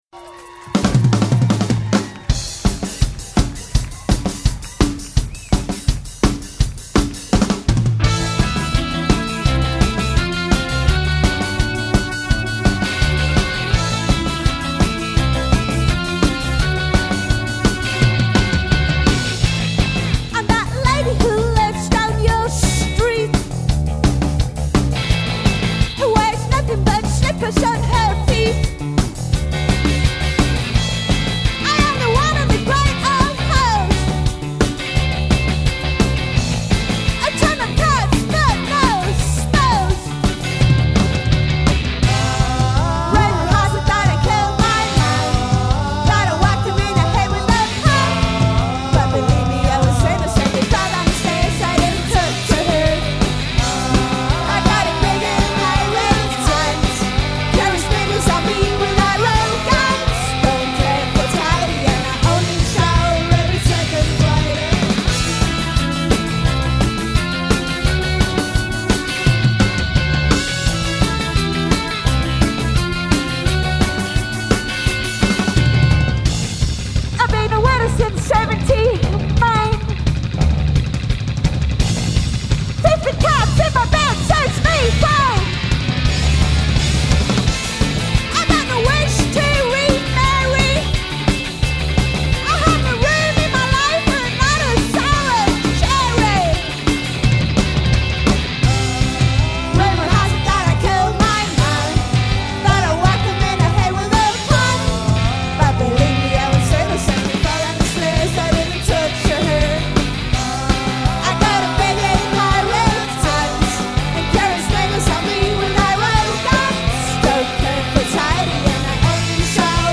registrata dal vivo lo scorso febbraio in Australia